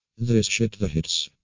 Play, download and share Sleepy With Tired original sound button!!!!
cannonball_jZnhd58.mp3